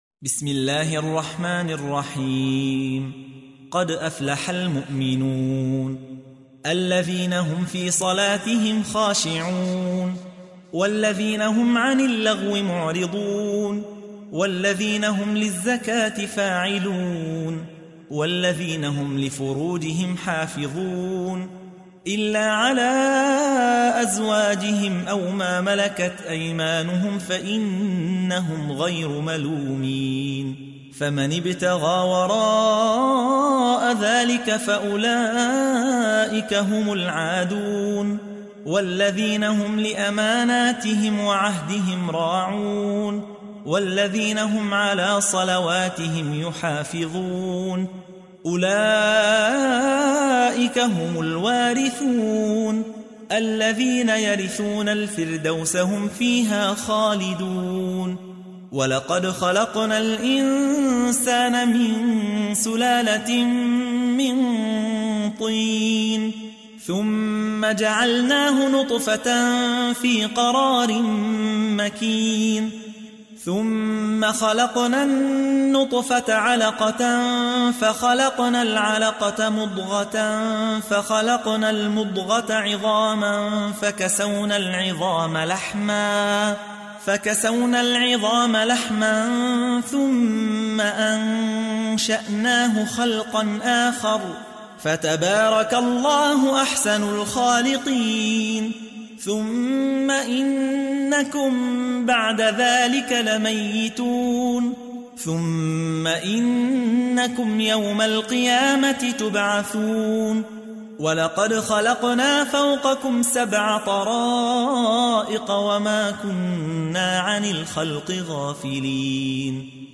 تحميل : 23. سورة المؤمنون / القارئ يحيى حوا / القرآن الكريم / موقع يا حسين